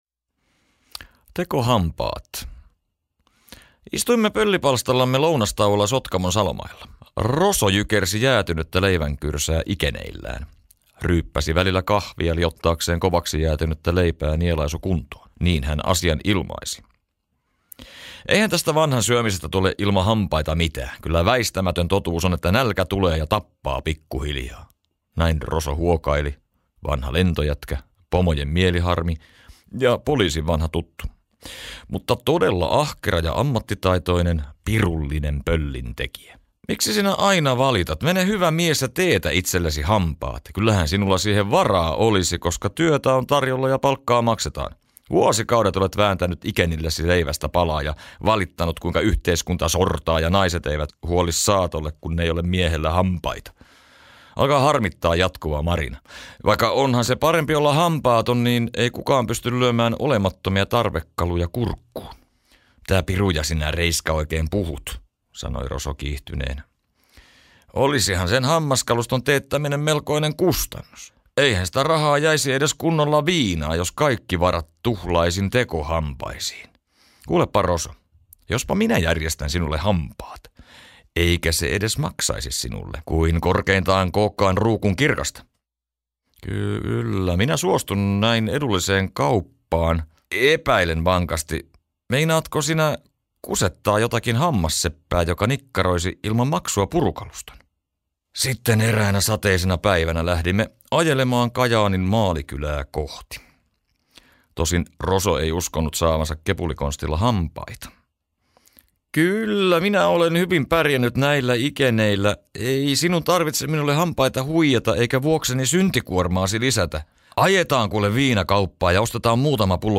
Lyhyitä kuunneltavia tarinoita